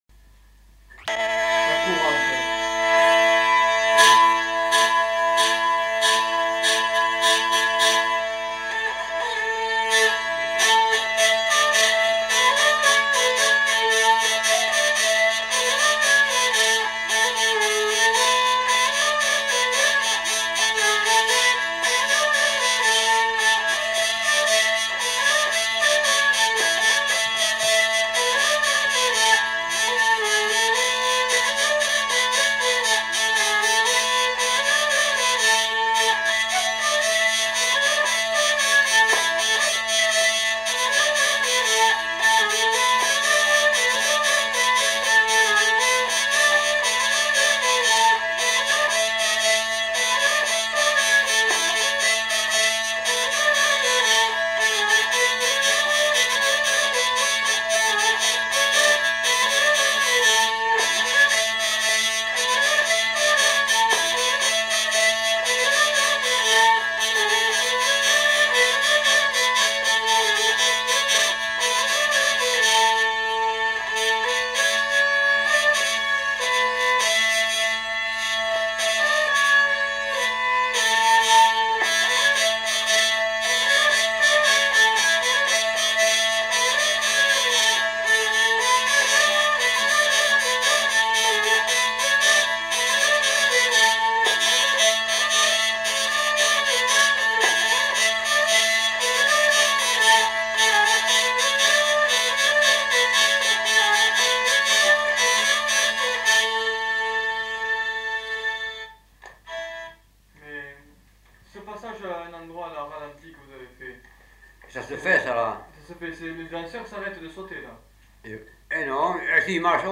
Aire culturelle : Gabardan
Lieu : Herré
Genre : morceau instrumental
Instrument de musique : vielle à roue
Danse : courante
Notes consultables : L'interprète donne des indications sur la manière de danser la partie lente.